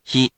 We’re going to show you the character, then you you can click the play button to hear QUIZBO™ sound it out for you.
In romaji, 「ひ」 is transliterated as 「hi」which sounds sort of like the hea in「heat